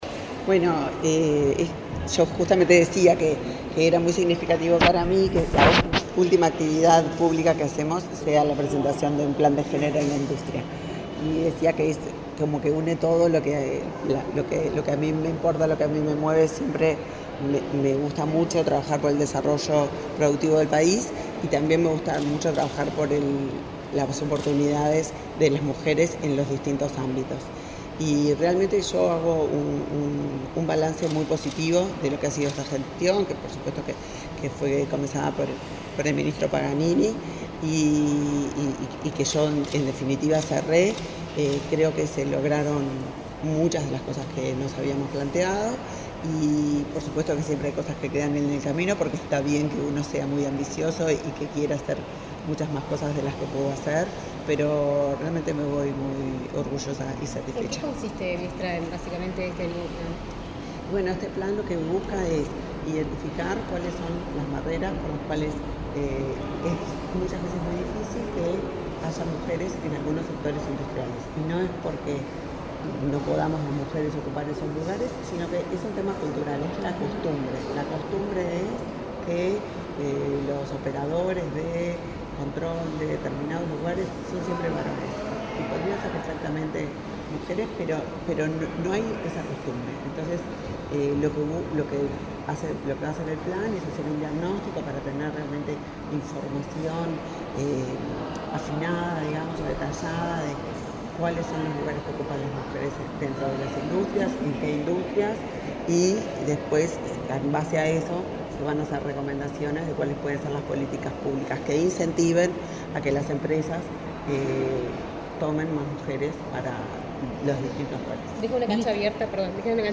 Declaraciones de la ministra de Industria, Elisa Facio
Este jueves 27 en la Torre Ejecutiva, la ministra de Industria, Elisa Facio, dialogó con la prensa, luego de participar en la presentación del Plan